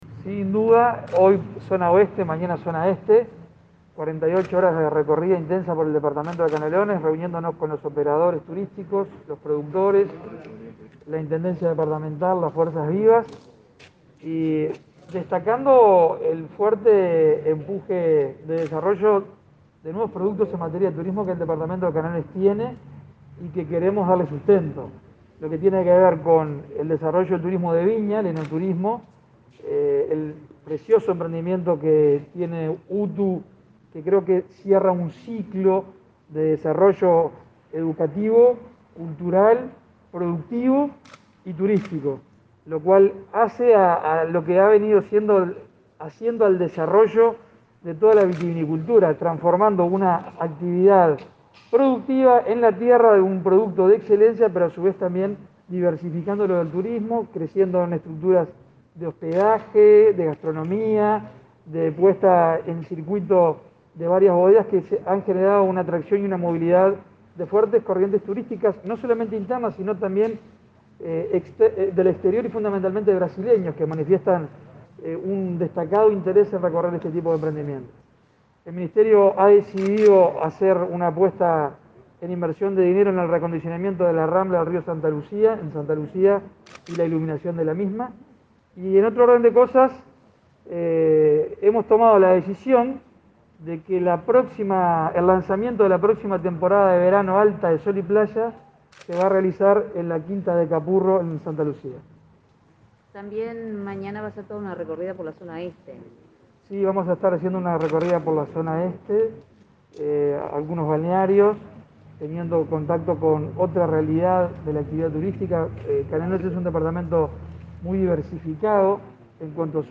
Declaraciones del ministro de Turismo, Germán Cardoso